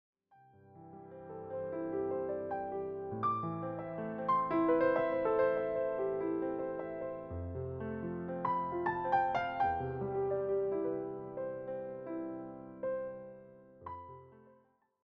presented in a smooth piano setting.
steady, easygoing tone